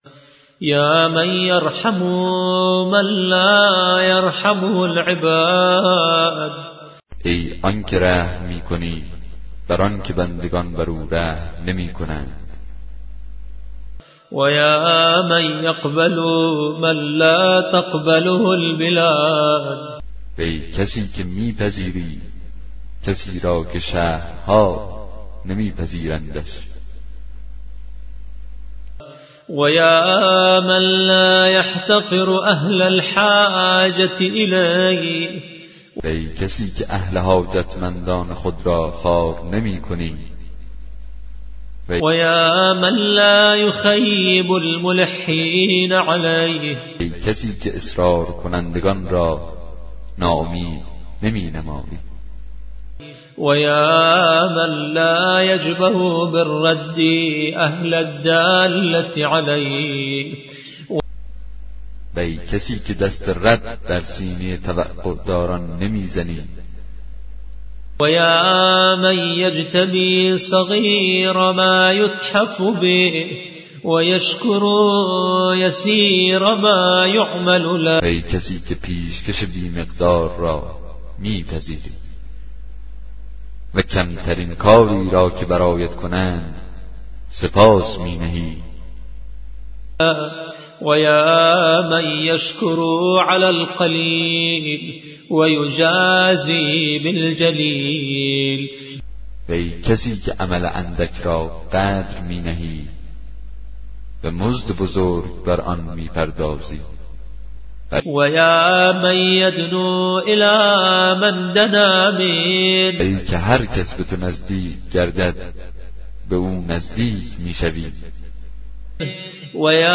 کتاب صوتی دعای 46 صحیفه سجادیه